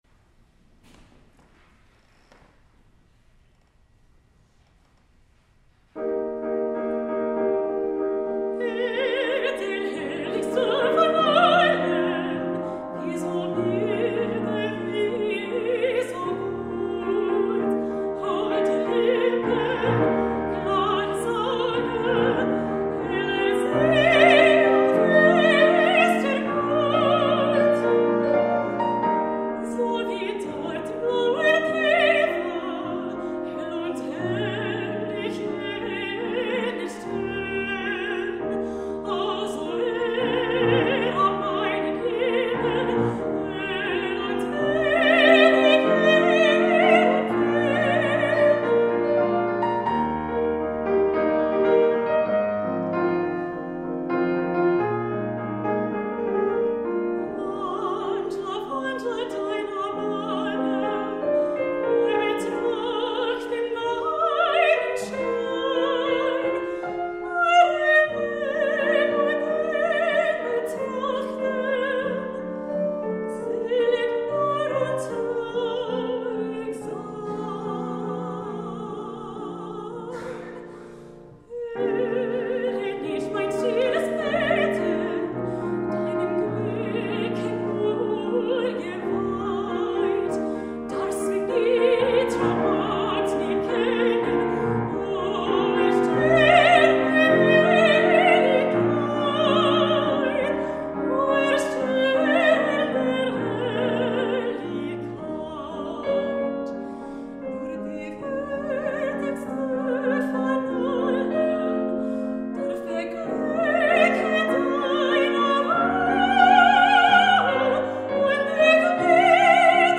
Mezzo-Soprano
Senior Recital